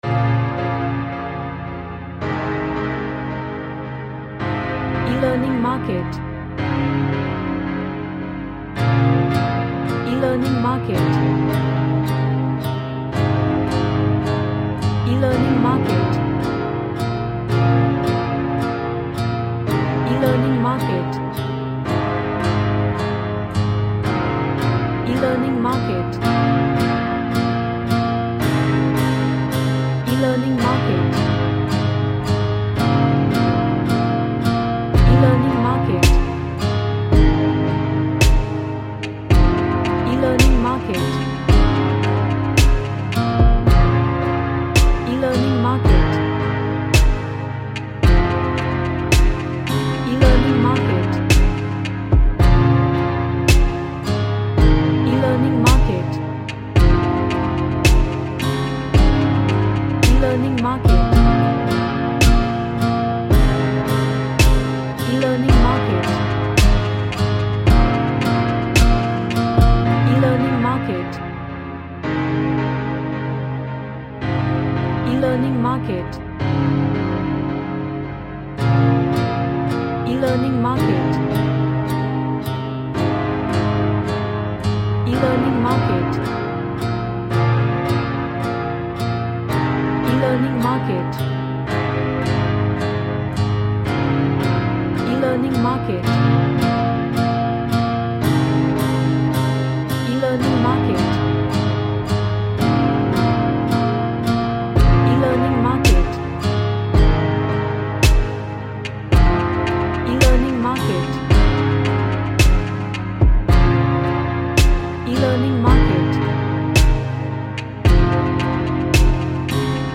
An ambient track, with acoustic vibes and melody.
Dark